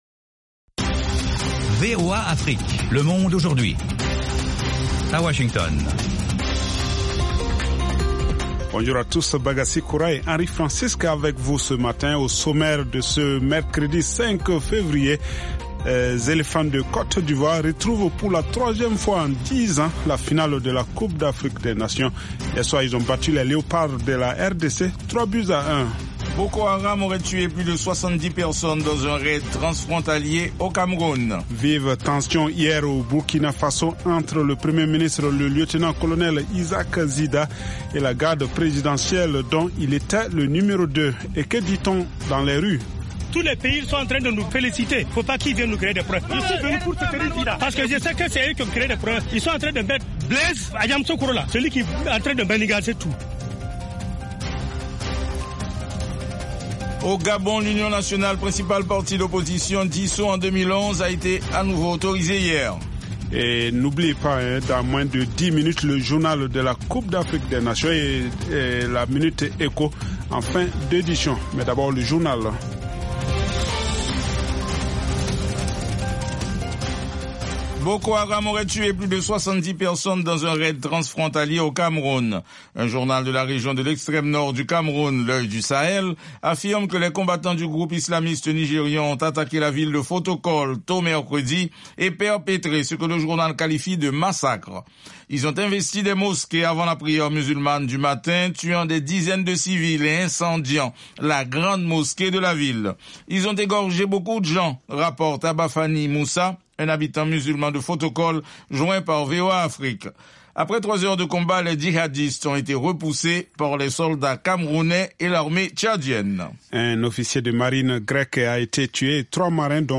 une sélection spéciale de musique malienne et internationale